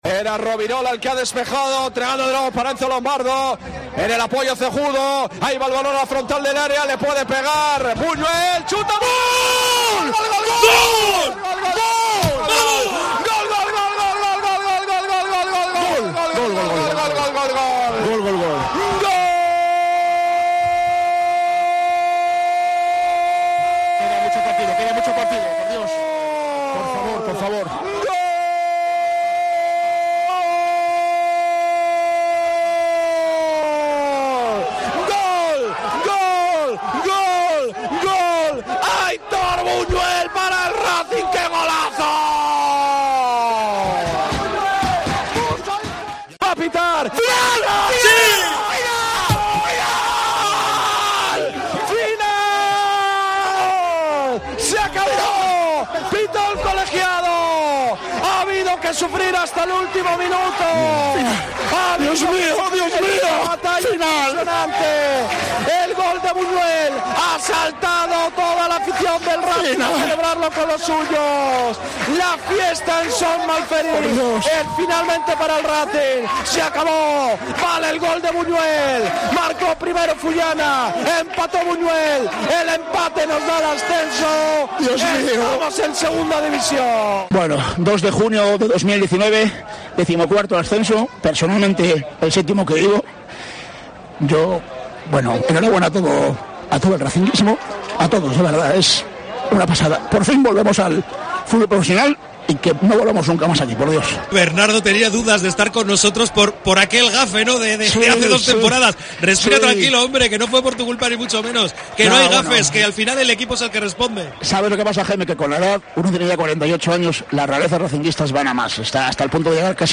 Cope Cantabria narró así el ascenso del Racing hace ya un año.